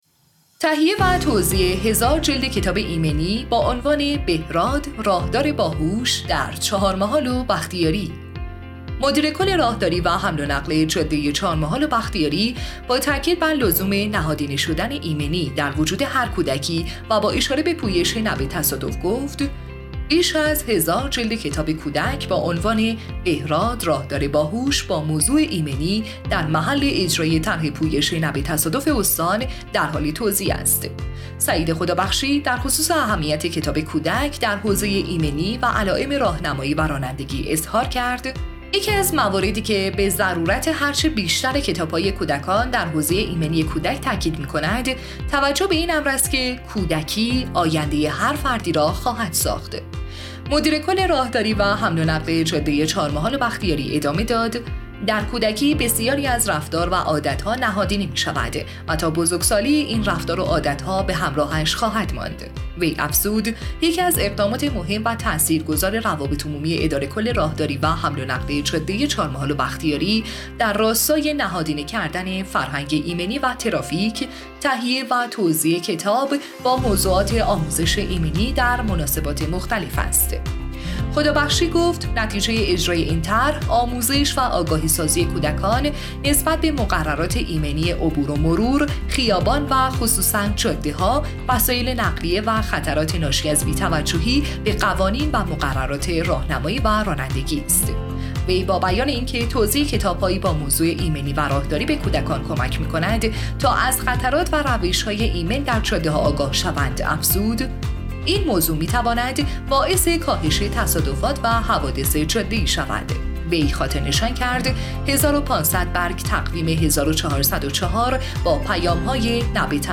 بشنوید| پادکست خبری توزیع بیش از ۱۰۰۰ جلد کتاب ایمنی در چهارمحال‌وبختیاری